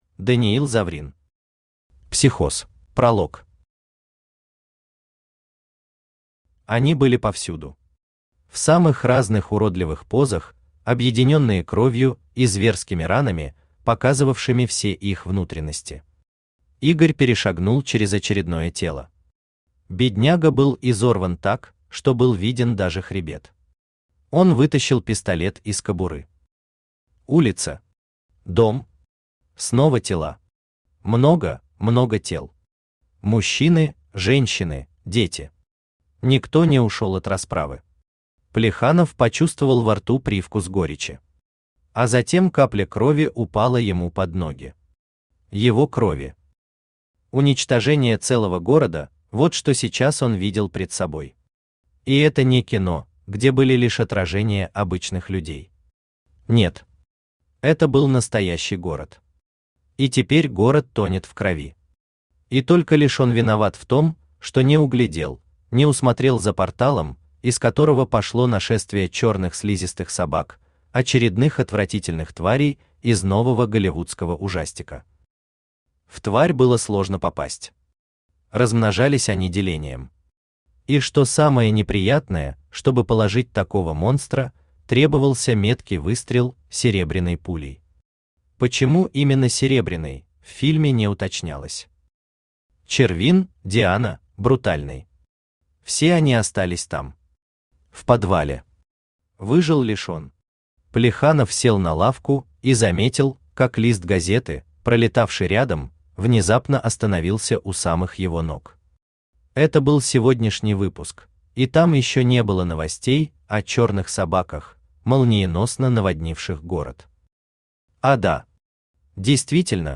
Aудиокнига Психоз Автор Даниил Заврин Читает аудиокнигу Авточтец ЛитРес.